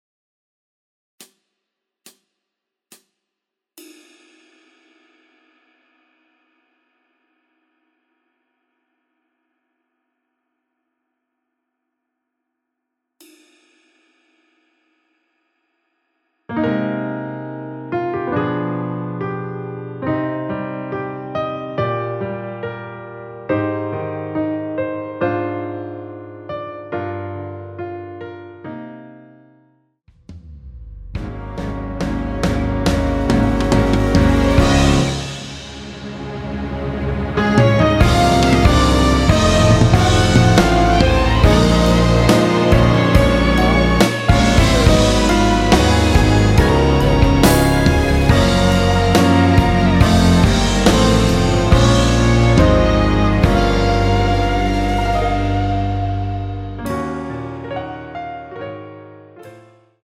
원키에서(-7)내린 MR입니다.
Eb
앞부분30초, 뒷부분30초씩 편집해서 올려 드리고 있습니다.
중간에 음이 끈어지고 다시 나오는 이유는